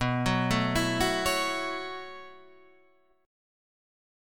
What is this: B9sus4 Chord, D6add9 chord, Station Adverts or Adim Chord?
B9sus4 Chord